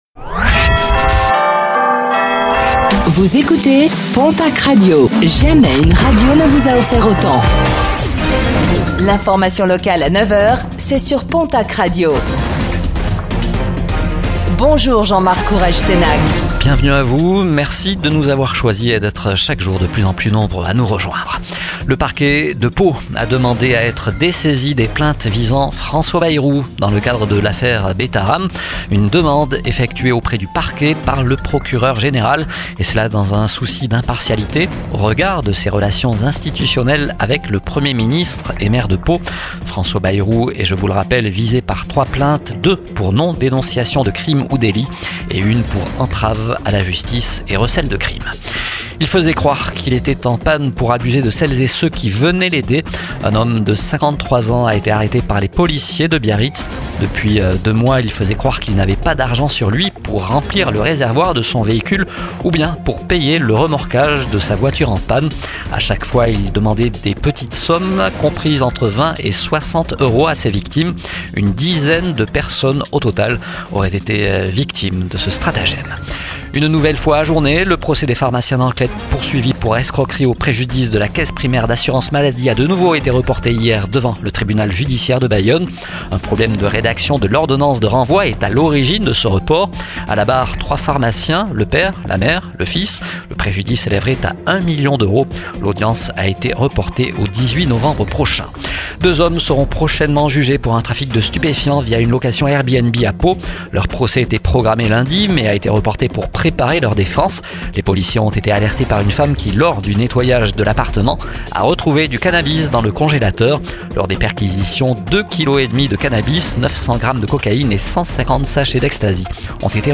Infos | Mercredi 14 mai 2025